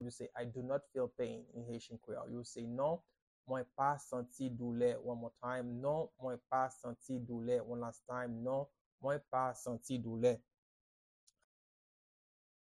Pronunciation and Transcript:
No-I-dont-feel-pain-in-Haitian-Creole-Non-mwen-pa-santi-doule-pronunciation-by-a-Haitian-teacher.mp3